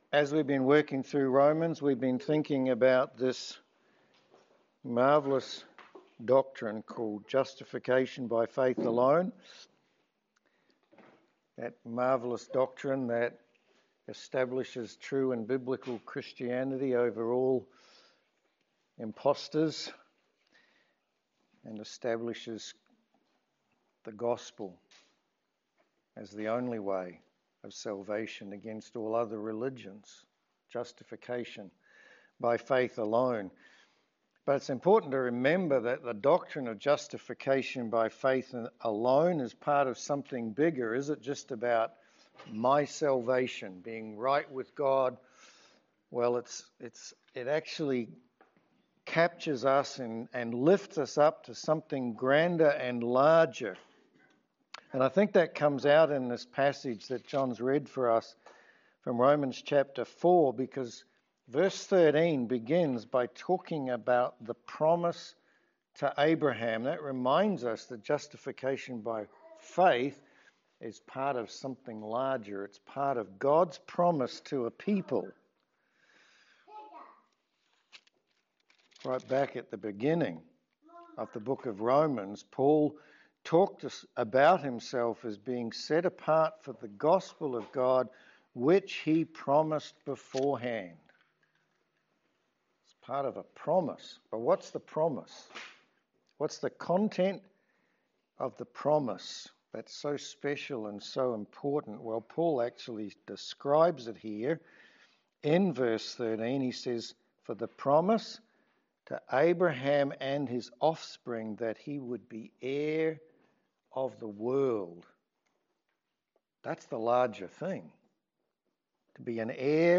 Romans 3:13-27 Service Type: Sermon In Romans 4 we get a picture of what faith really looks like.